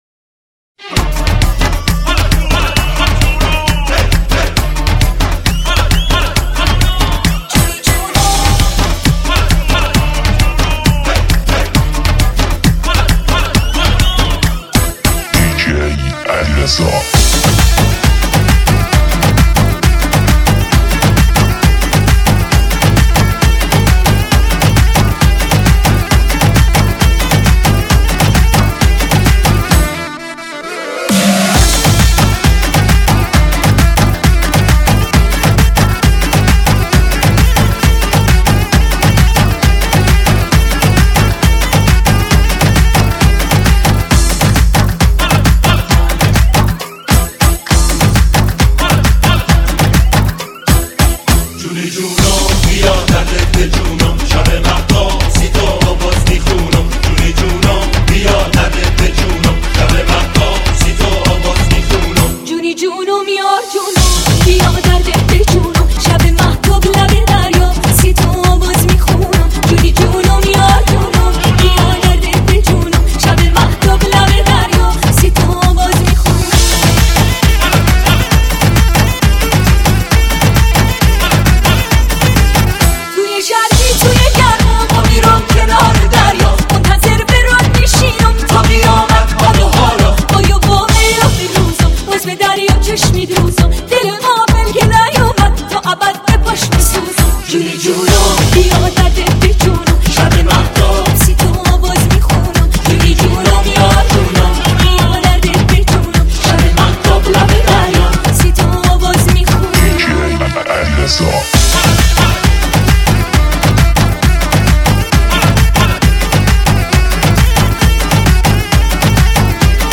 ریمیکس آهنگ بندری